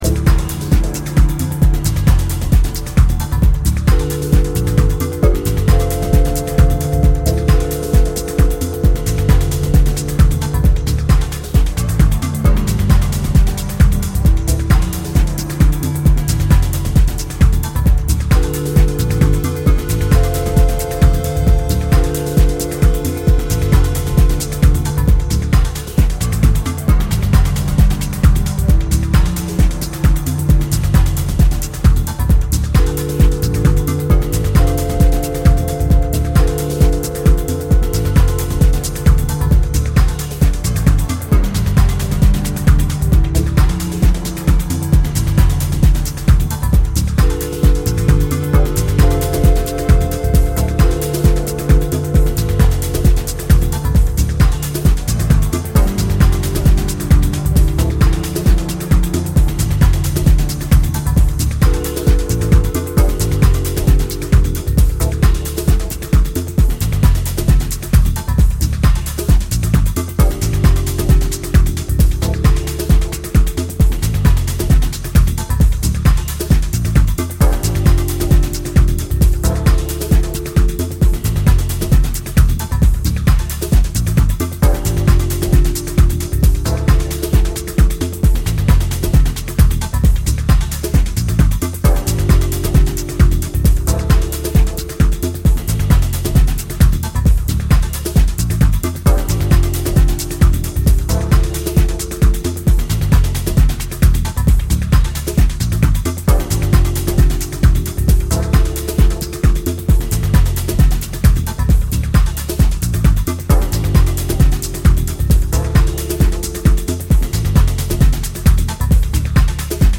micro-house